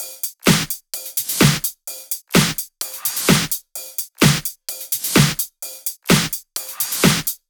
VFH2 128BPM Tron Quarter Kit 3.wav